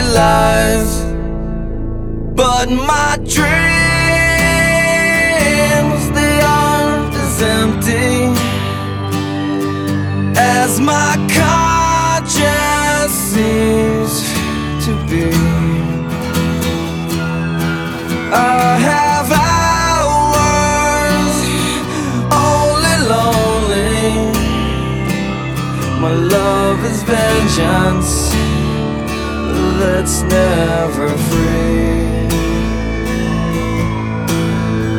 Жанр: Рок / Пост-хардкор / Хард-рок